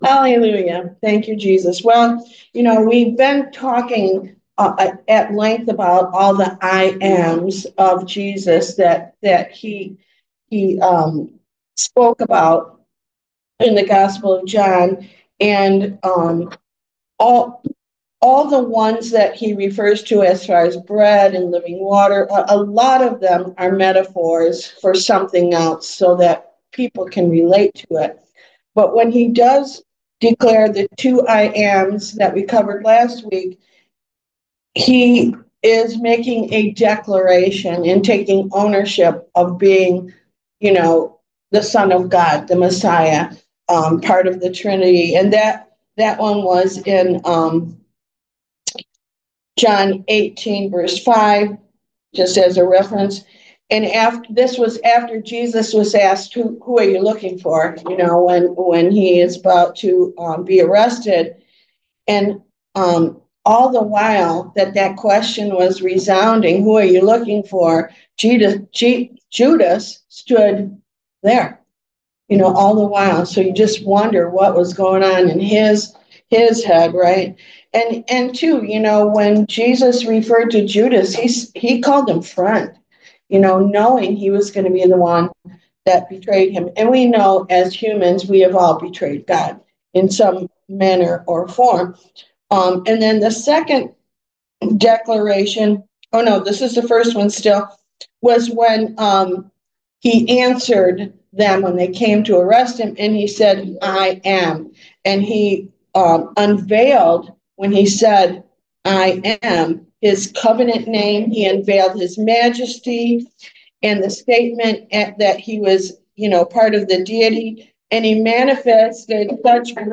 Service Type: Why Jesus Class